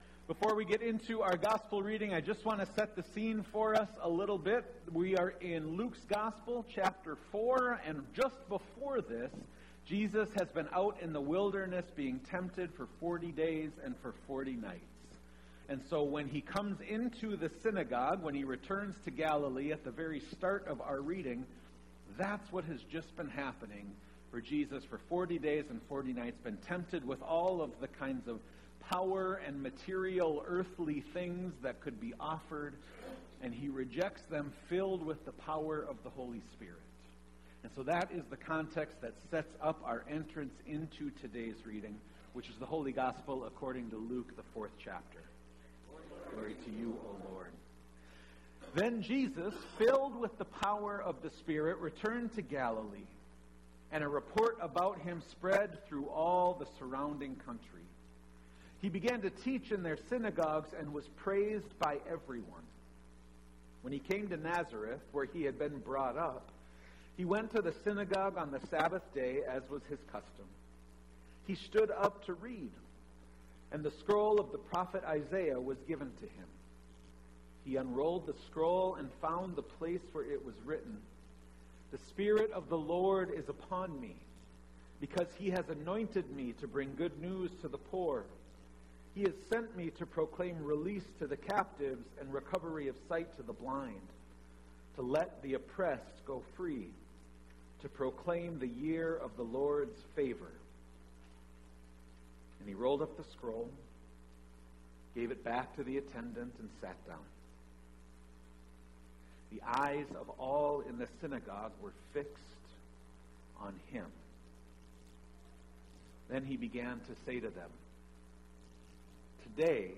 SERMON PODCAST
3rd Sunday after Epiphany